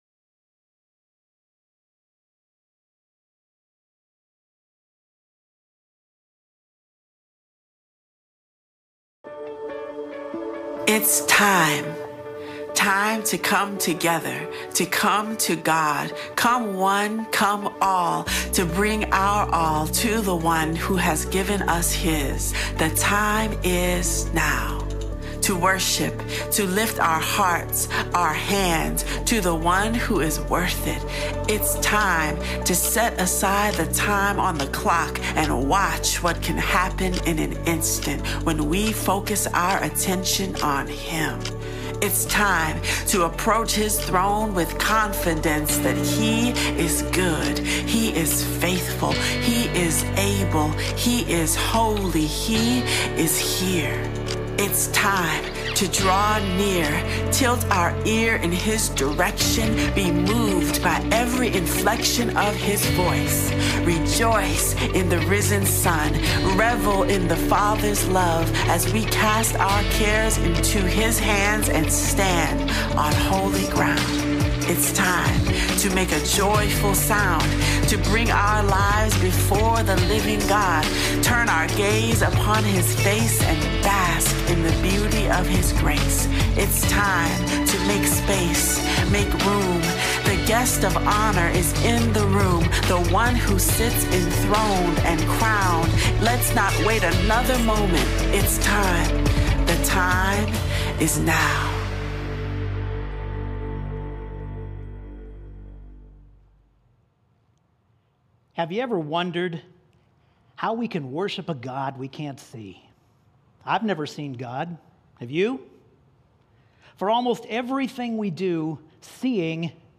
Are You A TRUE Worshipper? (2024-08-25) Sermon